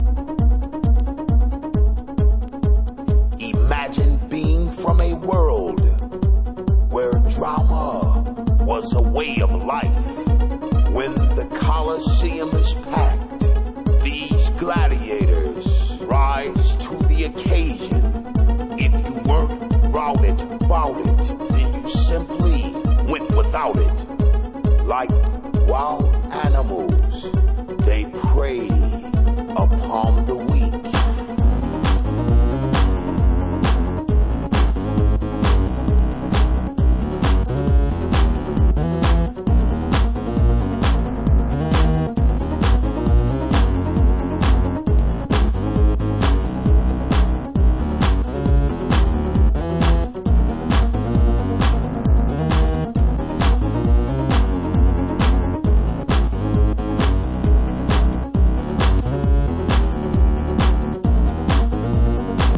P-funk electro-techno madness on this one.. Great!